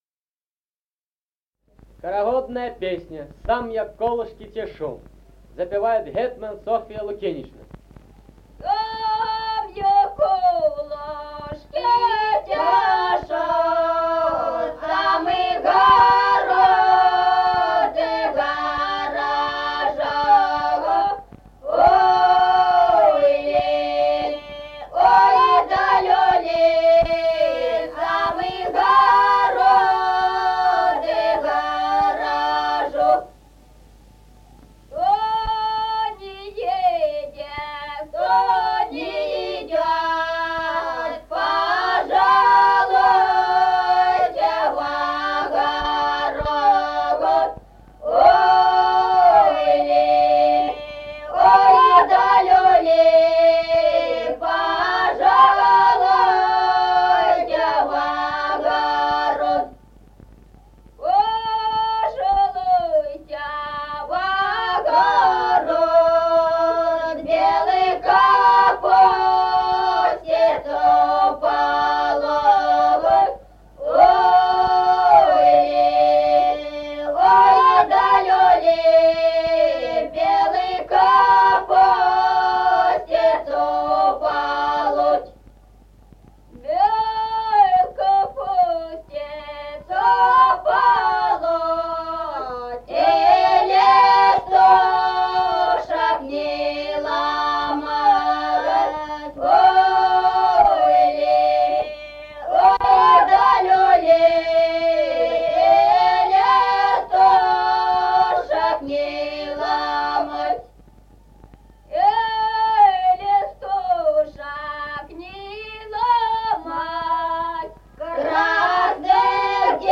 Музыкальный фольклор села Мишковка «Сам я колышки тешу», хороводная.